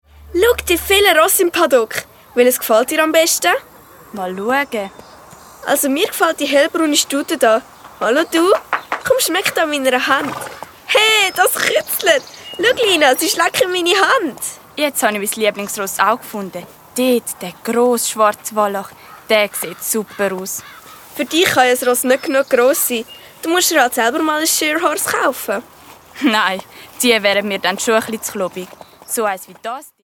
Hörspiel-CD mit Download-Code